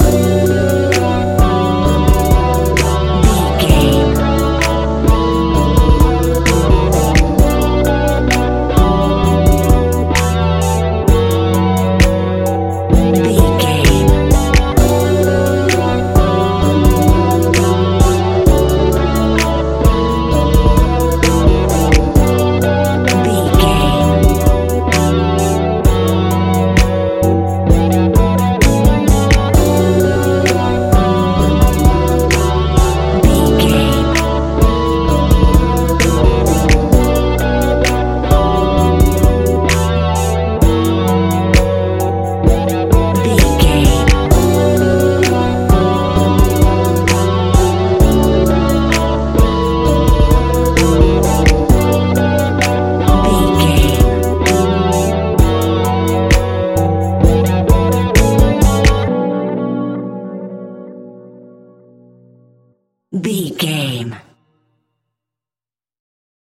Ionian/Major
B♭
laid back
Lounge
sparse
new age
chilled electronica
ambient
atmospheric